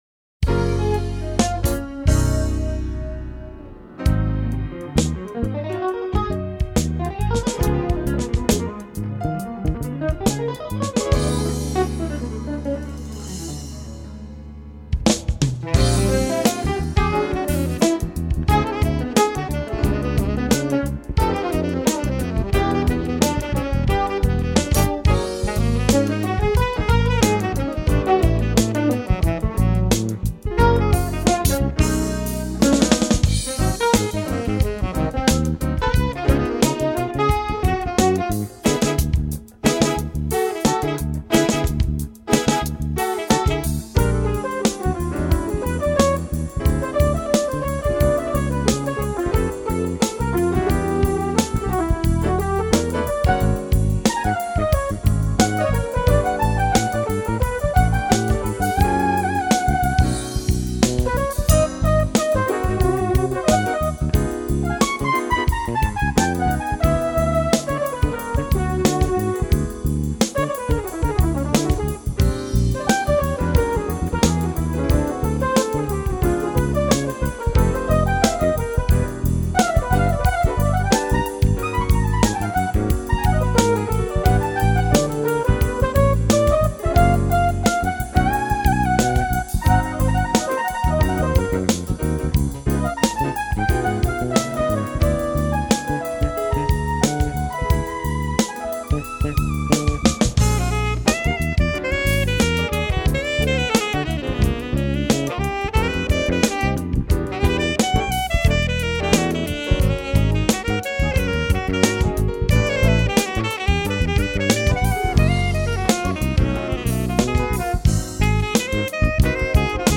949   05:48:00   Faixa:     Jazz